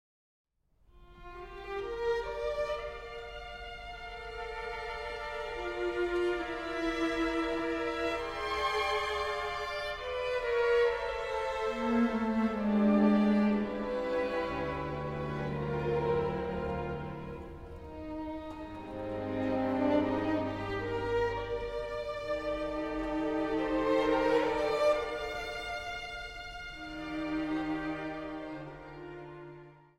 Zang | Mannenkoor